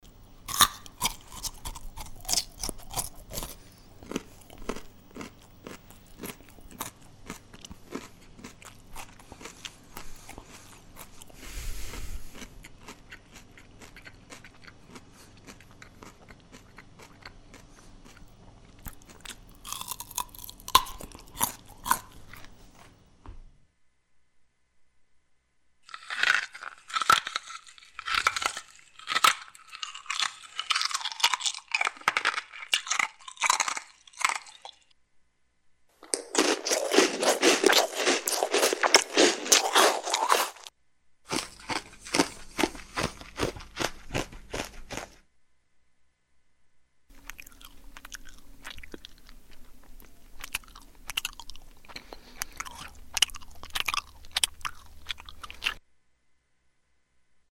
SFX – CHEWING (VARIOUS)
SFX-CHEWING-(VARIOUS).mp3